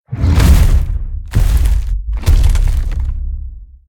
Sfx_creature_iceworm_spearattack_retract_01.ogg